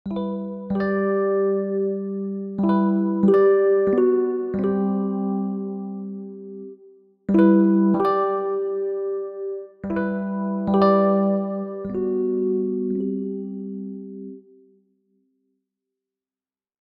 Remaining snotes are sent with an increasing delay.
With that change, playing a single key at a time sounds like this:
We took a look at some simple functionality to build upon the device — a filter which turns a single key played into a strummed chord with a pedal tone, and a bank-switcher which sets the channel of all further events from the hardware device.
Rhodes_strummed_pedal_tone.mp3